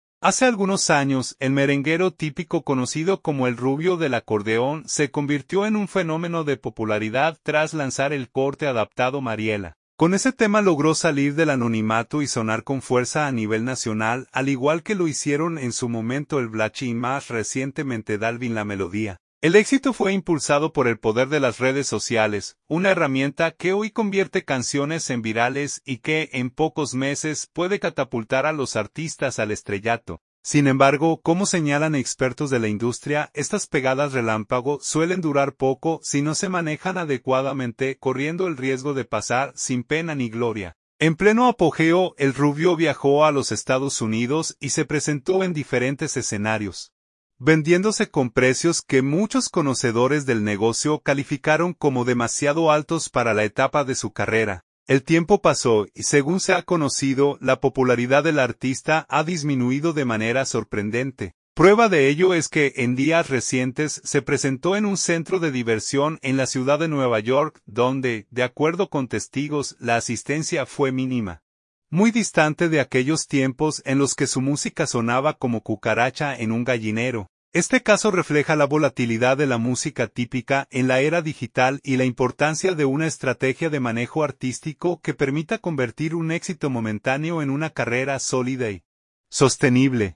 merenguero típico